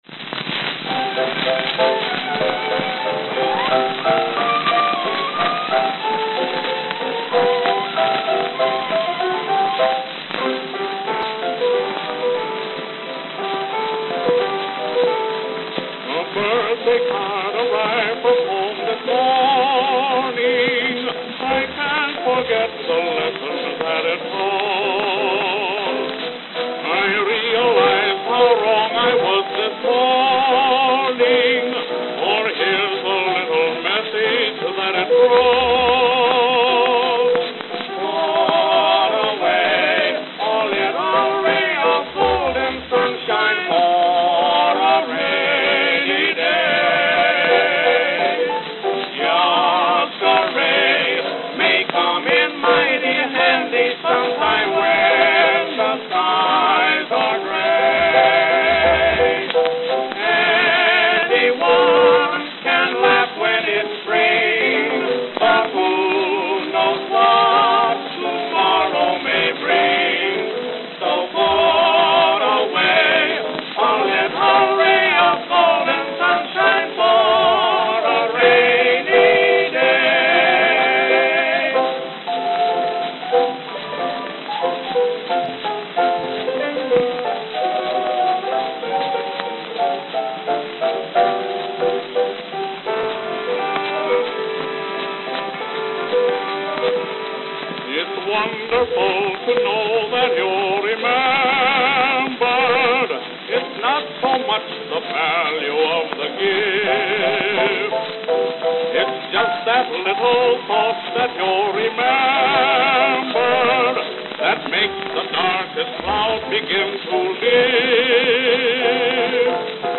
Note: Very worn. Crack one-inch inward, not audible.